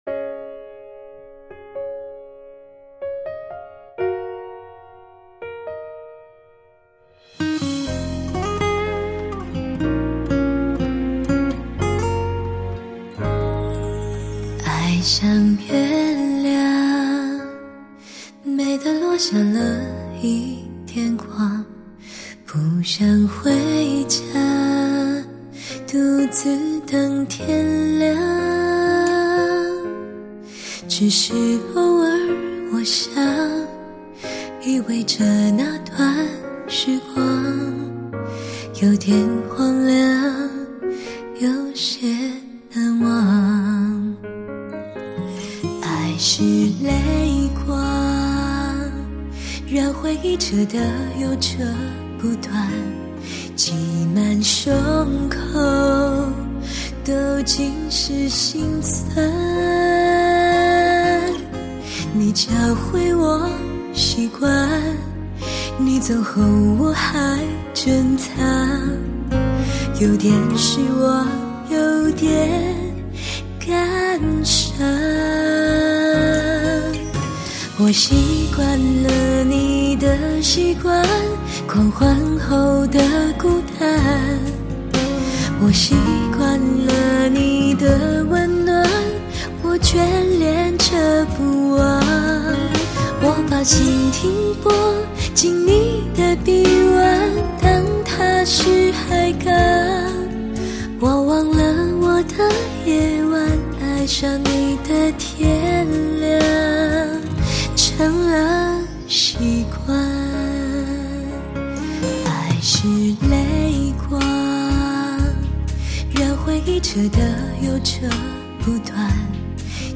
品味如陈年红酒一般温柔厚实的女声，感受复古典范，聆听极致发烧……
非常好听的女声